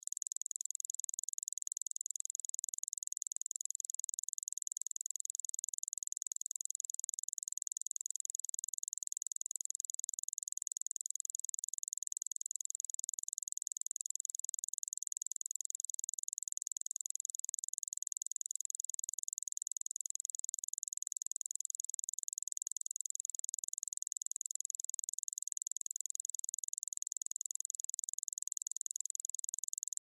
Звуки секундомера
Звук секундной стрелки в движении 30 секунд